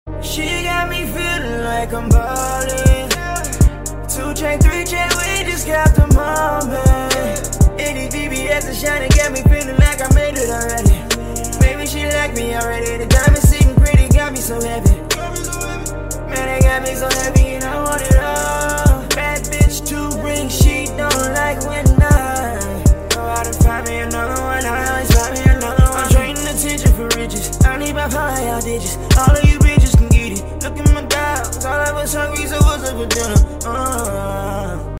Ringtones Category: Rap - Hip Hop